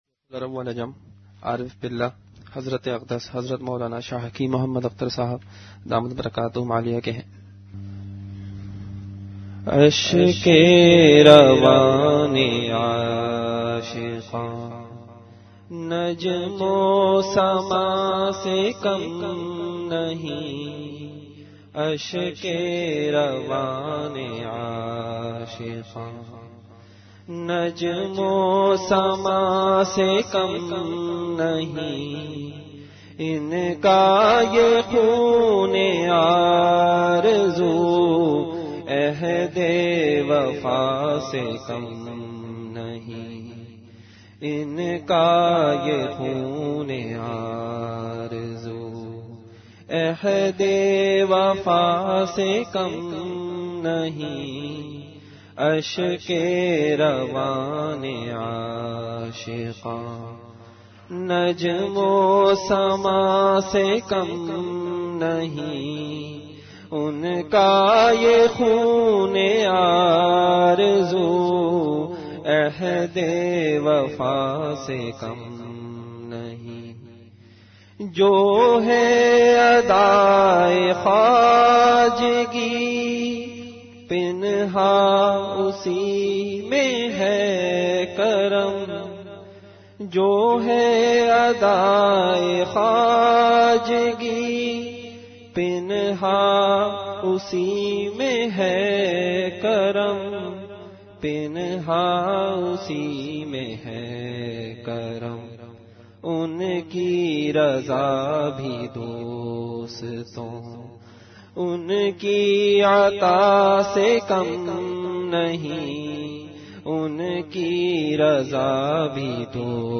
Category Majlis-e-Zikr
Event / Time After Isha Prayer